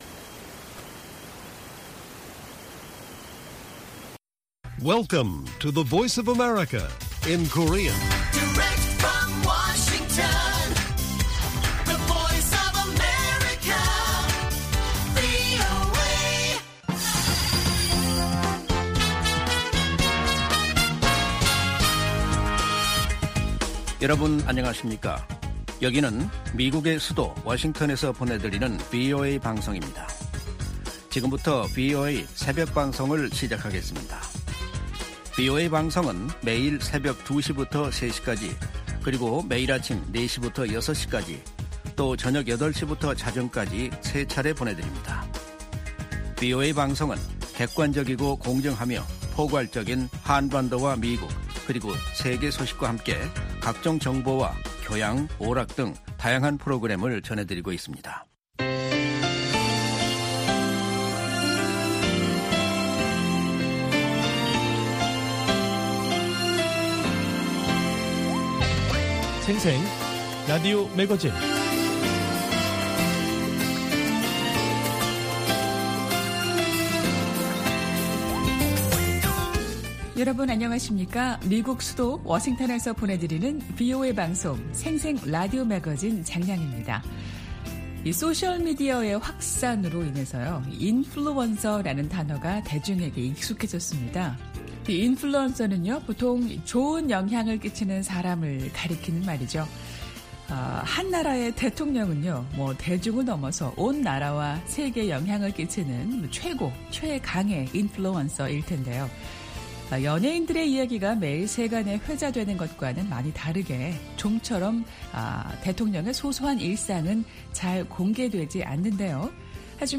VOA 한국어 방송의 일요일 새벽 방송입니다.